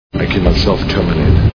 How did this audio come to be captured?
Terminator 2 Movie Sound Bites